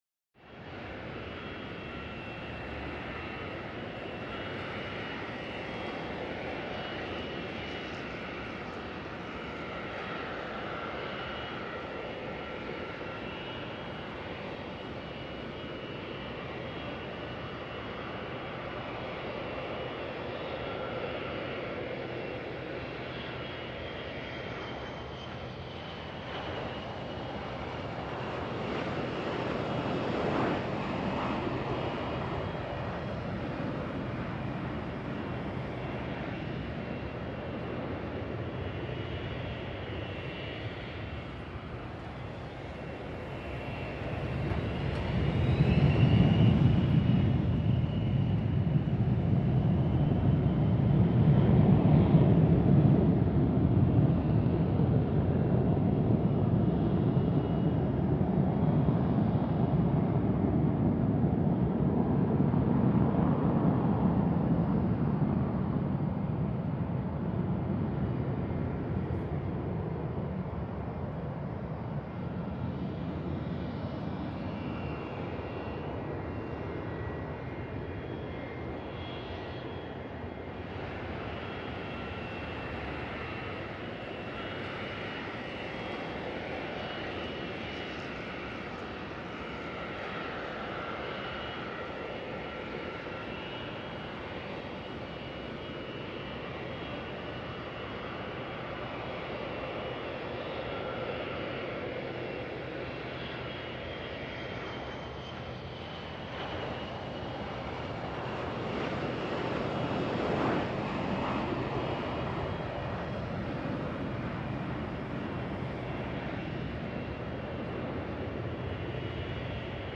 Airport Ambience - Various Jets Taking Off